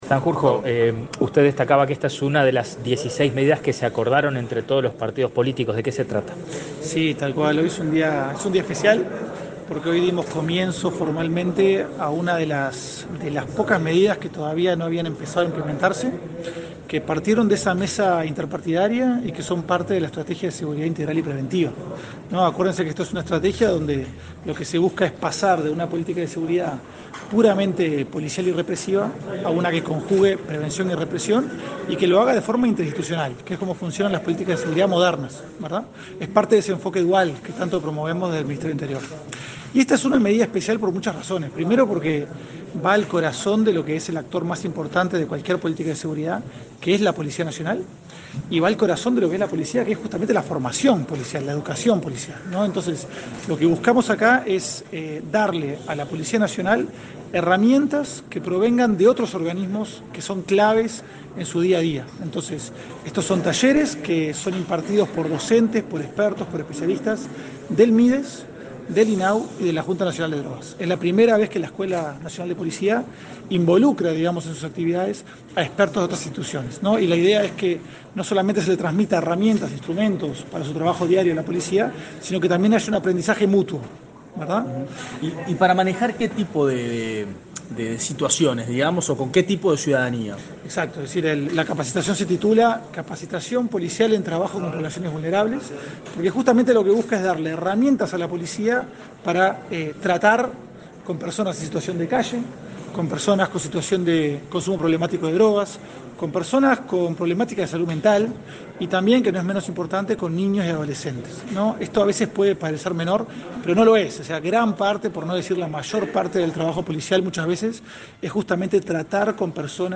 Declaraciones a la prensa del coordinador de Estrategias Focalizadas de Prevención Policial del Delito, Diego Sanjurjo
Tras participar en la presentación de la capacitación para policías en el trabajo con personas vulnerables, que será impartido por técnicos de la Junta Nacional de Drogas, el INAU y el Mides, el coordinador de Estrategias Focalizadas de Prevención Policial del Delito, Diego Sanjurjo, realizó declaraciones a la prensa este 22 de julio.